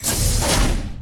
doorsopen.ogg